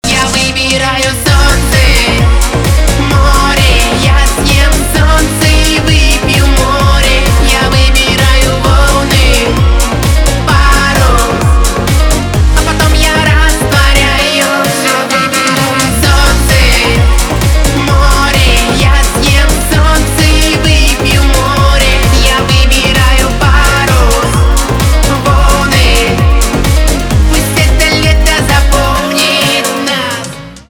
поп
позитивные , битовые , басы , качающие , кайфовые